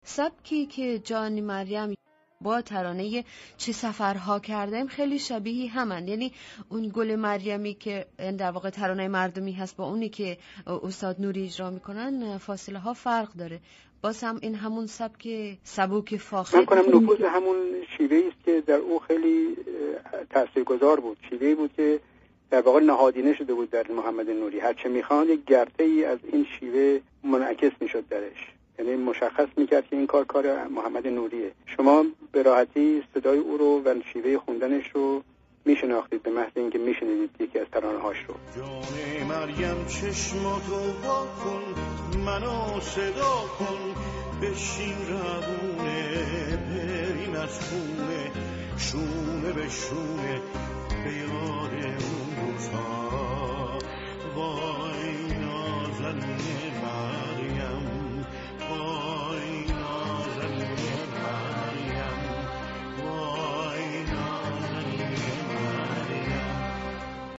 Radio Zamaneh Interview
The following words have been removed from the sentences in Part 5 and replaced with  a pluck: